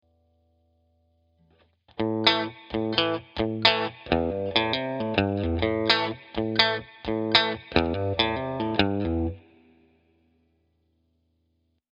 Adding Hammer-ons & Pull-offs
In order to make your riffs sound more fluent we can add hammer-ons and pull-offs, just as in the tablature below.